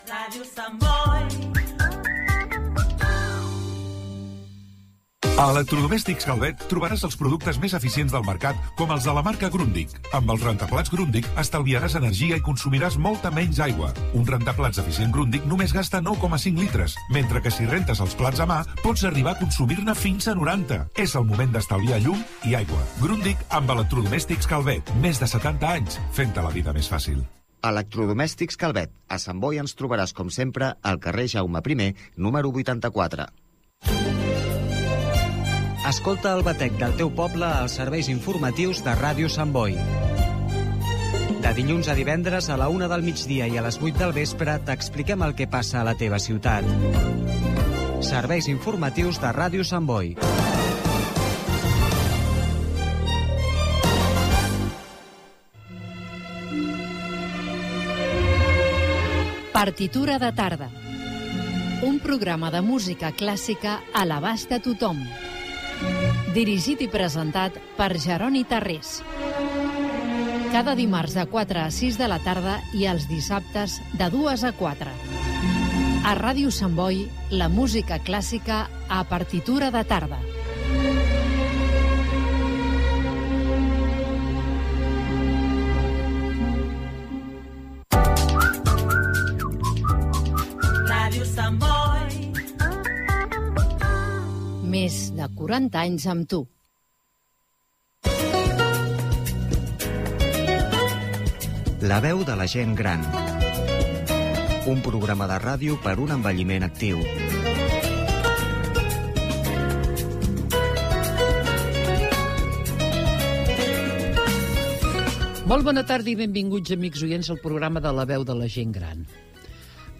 Indicatiu, publicitat, promoció dels serveis informatius i de "Partitura de tarda", indicatiu. Presentació del programa 500 de "La veu de la gent gran" repassant la seva història, salutació del fill d'una col·laboradora i presentació dels col·laboradors
Entreteniment
FM